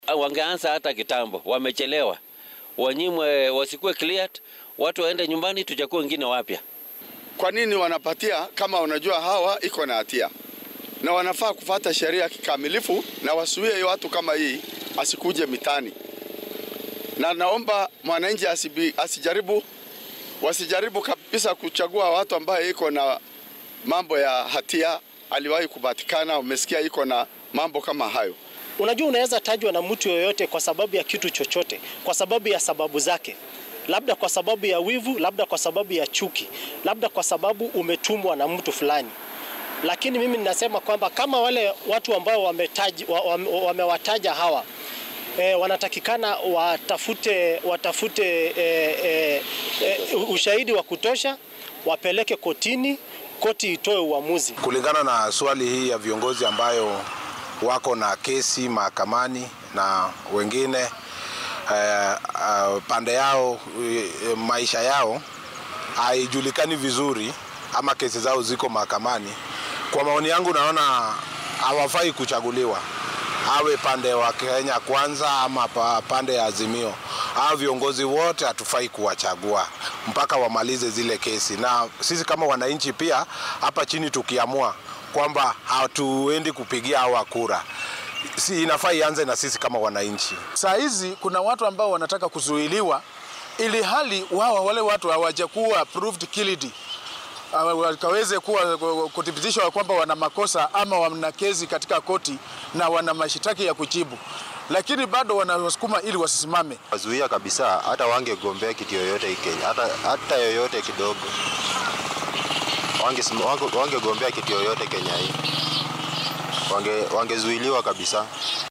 Kenyaanka oo ka hadlay arrintaan ayaa sheegay in aan loo baahneen in markii uu siyaasi xil qabto lagu soo oogo dacwado.